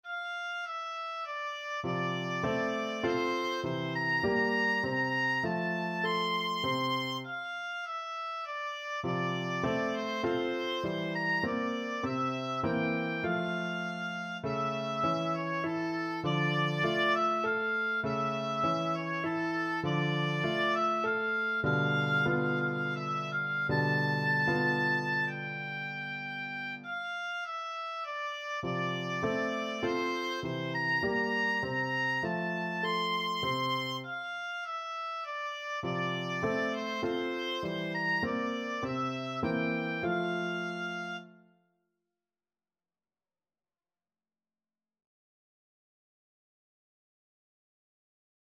Classical Chopin, Frédéric A Wish Oboe version
Oboe
F major (Sounding Pitch) (View more F major Music for Oboe )
3/4 (View more 3/4 Music)
Allegro moderato (View more music marked Allegro)
Classical (View more Classical Oboe Music)